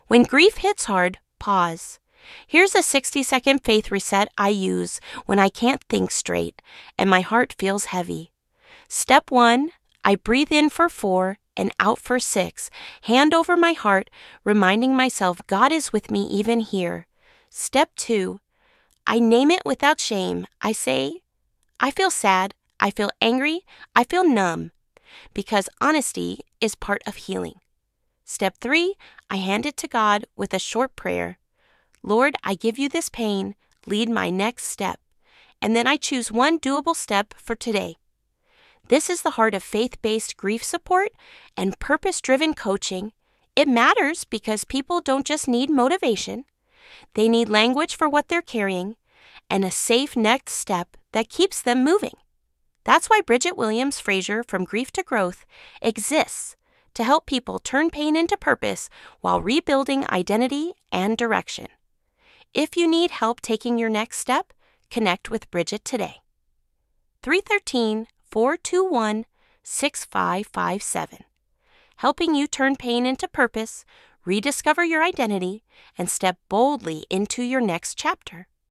Voiceover video (**fully evergreen; no holiday mention**) designed for **March 20, 2026 and beyond**: deliver a gentle 3-step “faith reset” for grief-heavy moments: (1) Breathe + place your hand over your heart, (2) Name the feeling without shame, (3) Give it to God in a short prayer and choose one next step for today.